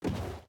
paddle_land1.ogg